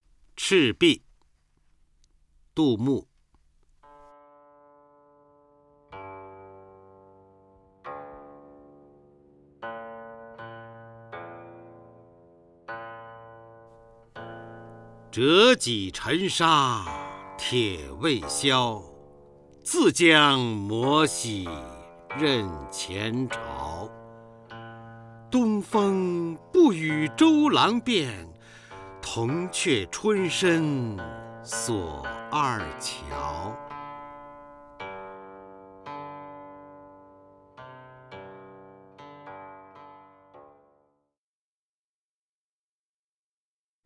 方明朗诵：《赤壁》(（唐）杜牧) （唐）杜牧 名家朗诵欣赏方明 语文PLUS